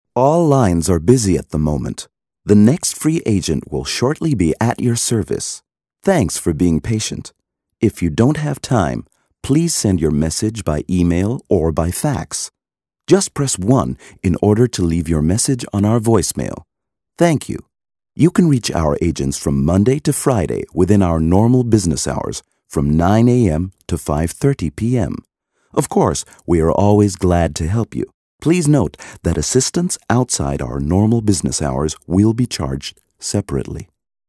mid-atlantic
Sprechprobe: Sonstiges (Muttersprache):
The warm but serious tone of his voice lends itself to many situations, where an American is required to speak in a " European" context.